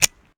weapon_foley_drop_09.wav